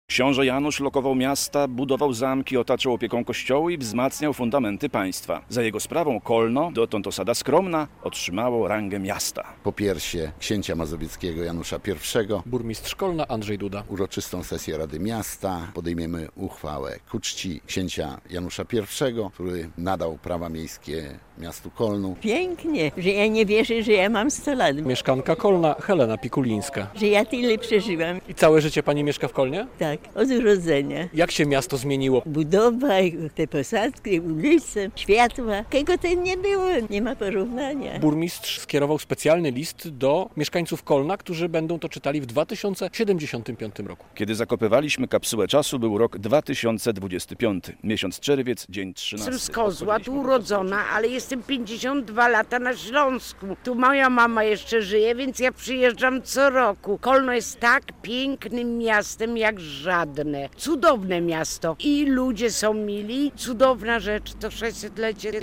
600 lat Kolna. Miasto świętuje wielki jubileusz - relacja